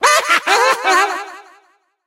evil_gene_vo_09.ogg